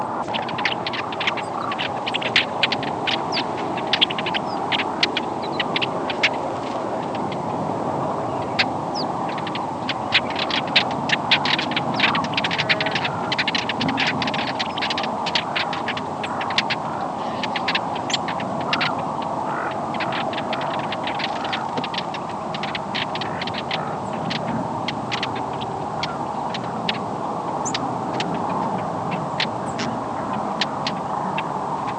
Brewer's Blackbird diurnal flight calls
Diurnal calling sequences:
Birds in flight with Sandhill Cranes and Horned Lark in the background.